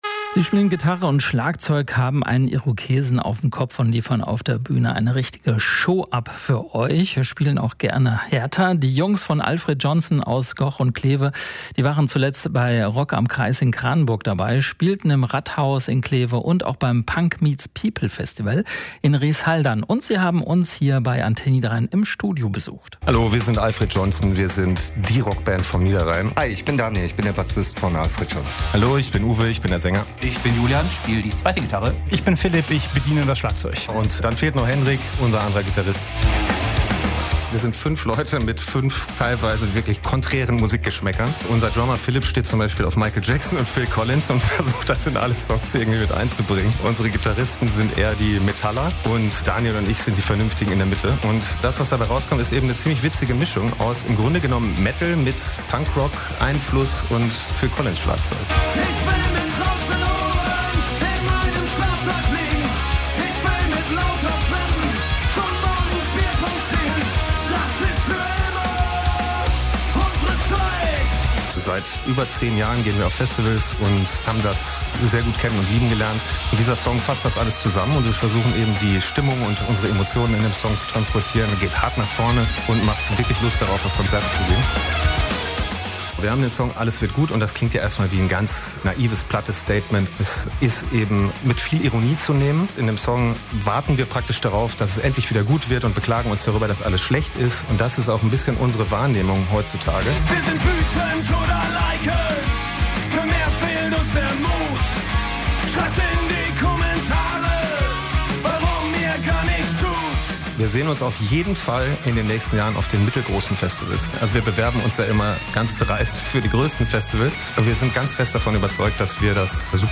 Vor kurzem waren sie bei uns im Studio zu Besuch.
alfred_johnson_bandvorstellung.mp3